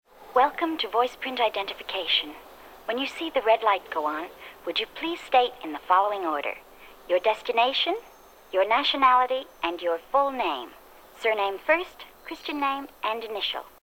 enregistré à Herblay